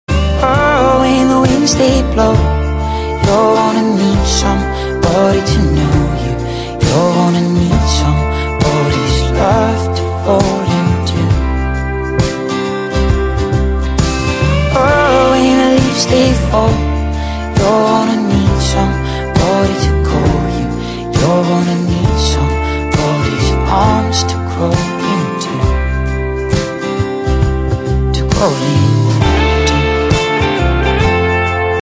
M4R铃声, MP3铃声, 欧美歌曲 36 首发日期：2018-05-14 19:53 星期一